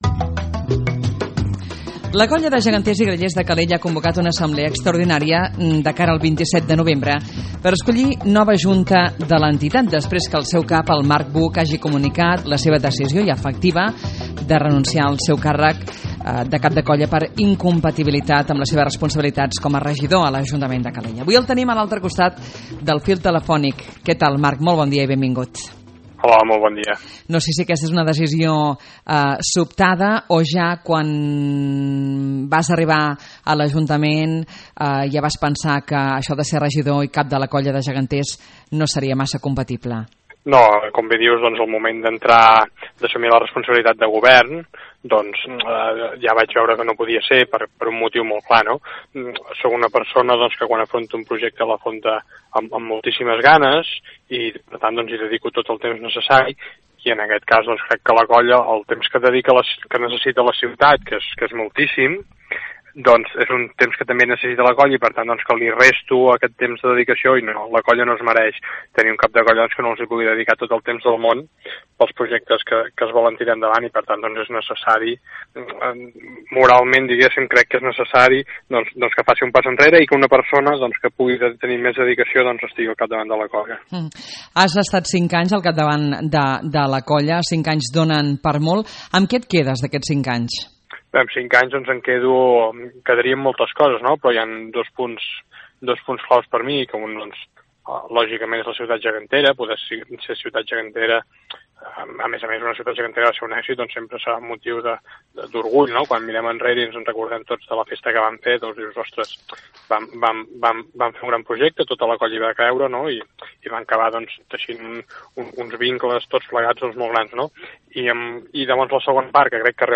Entrevista a Marc Buch